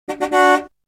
جلوه های صوتی
دانلود صدای ماشین 6 از ساعد نیوز با لینک مستقیم و کیفیت بالا